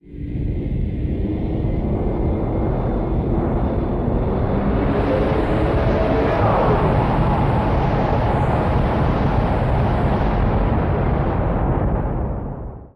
Звук работы турбоускорителей